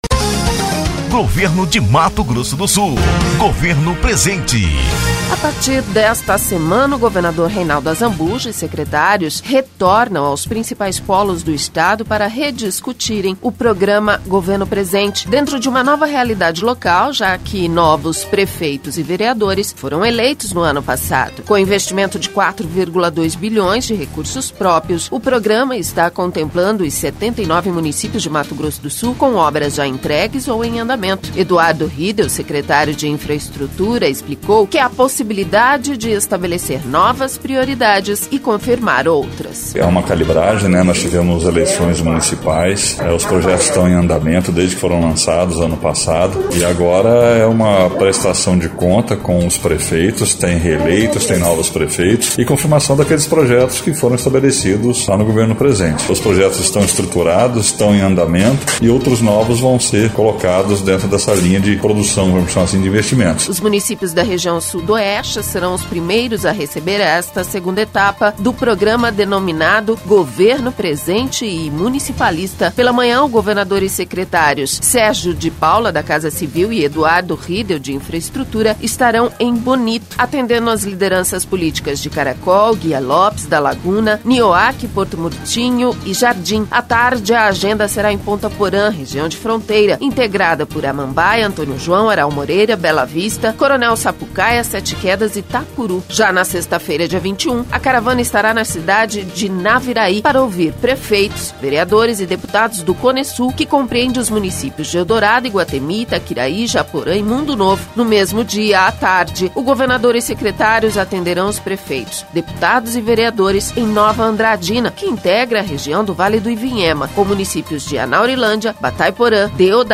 Confira as informações com a repórter